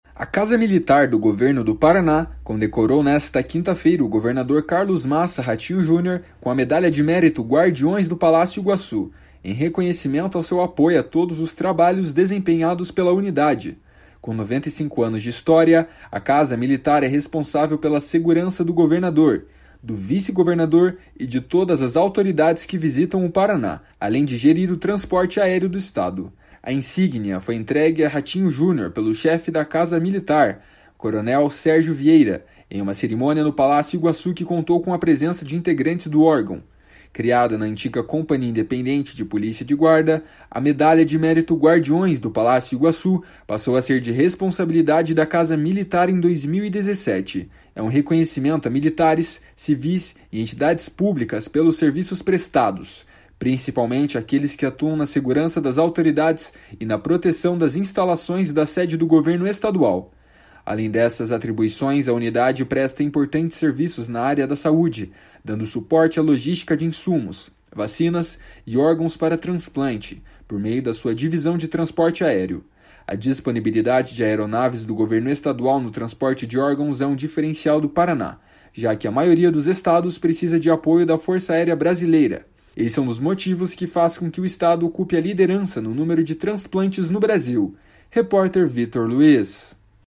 A insígnia foi entregue a Ratinho Junior pelo chefe da Casa Militar, coronel Sérgio Vieira, em uma cerimônia no Palácio Iguaçu que contou com a presença de integrantes do órgão.